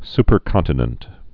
(spər-kŏntə-nənt)